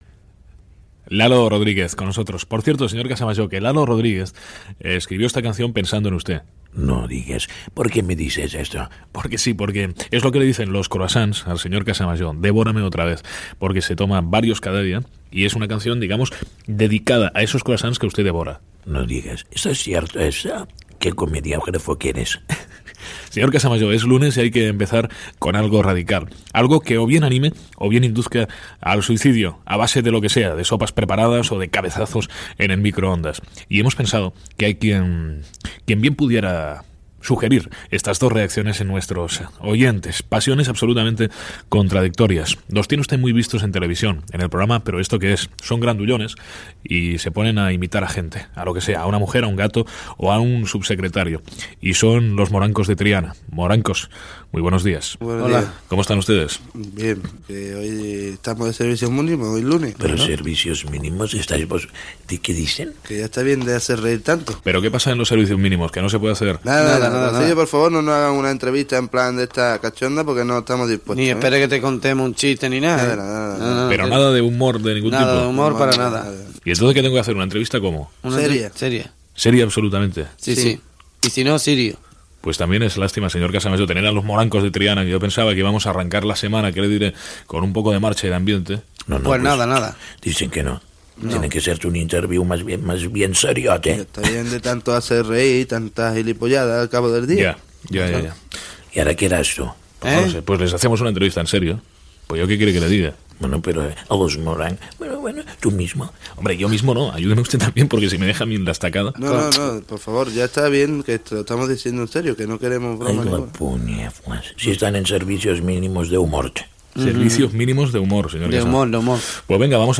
Comentari sobre un tema musical i entrevista a César i Jorge Cadaval del dúo Los Morancos de Triana
Entreteniment